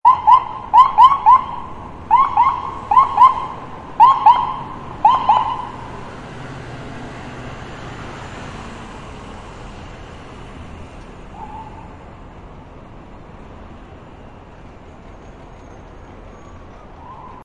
Siren Blips Sound Button - Free Download & Play